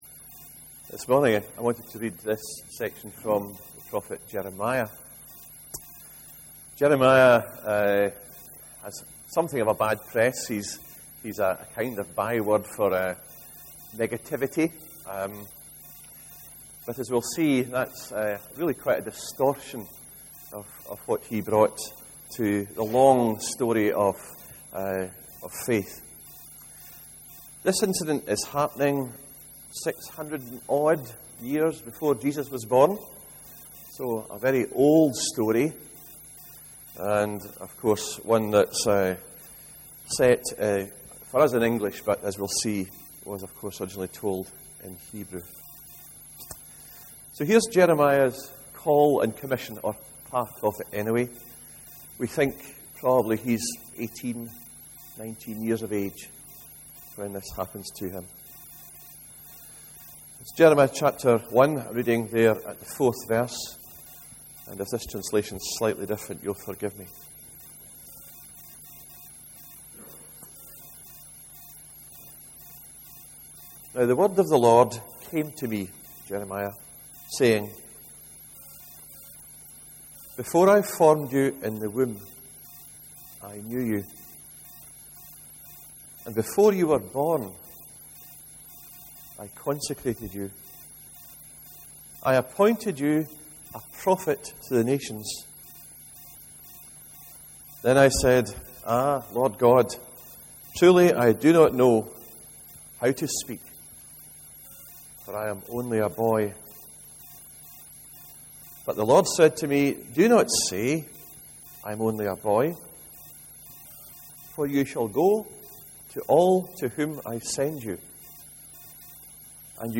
29/09/13 sermon – What we notice compared to God’s vision (Jeremiah 1:4-12)